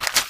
High Quality Footsteps
STEPS Newspaper, Walk 03.wav